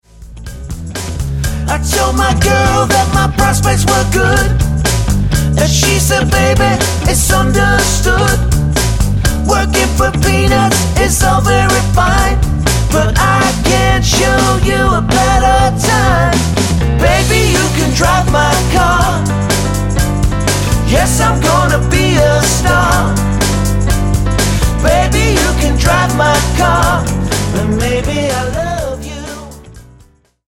--> MP3 Demo abspielen...
Tonart:D Multifile (kein Sofortdownload.